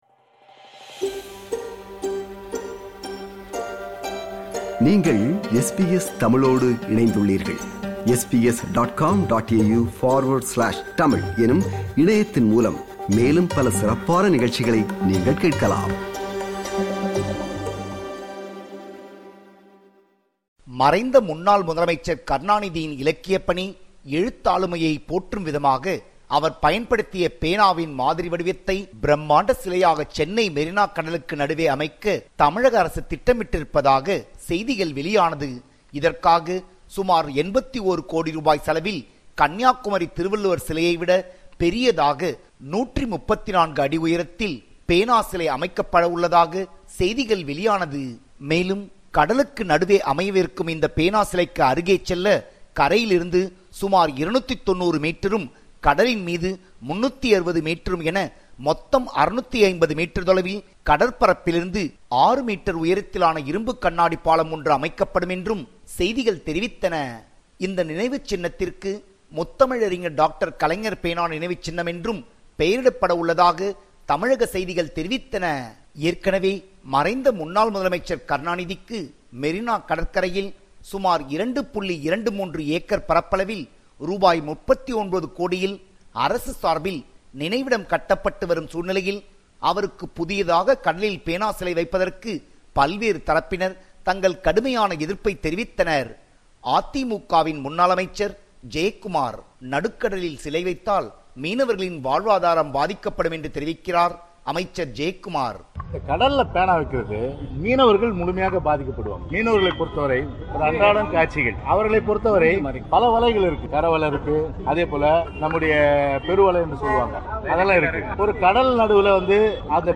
compiled a report focusing on major events/news in Tamil Nadu / India.